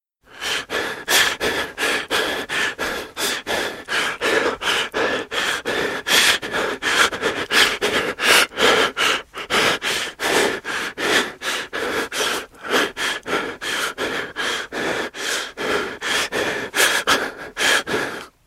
Рингтон Парень быстро дышит
Звуки на звонок